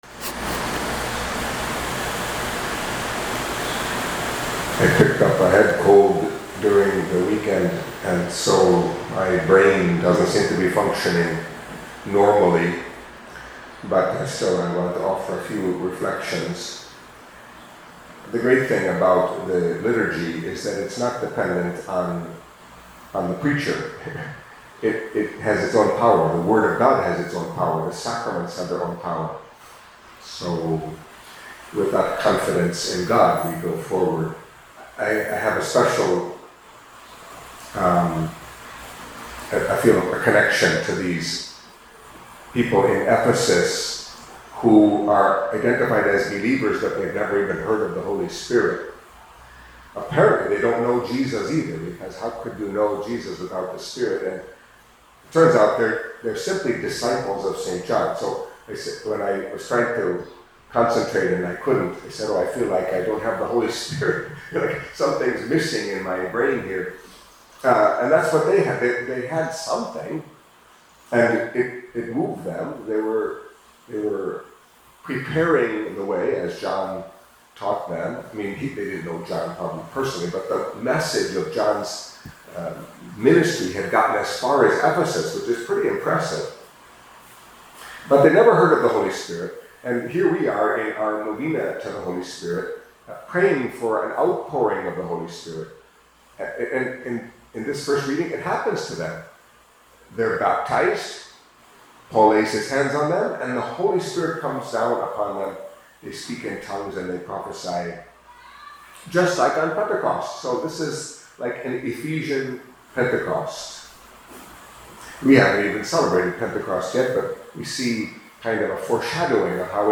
Catholic Mass homily for Monday of the Seventh Week of Easter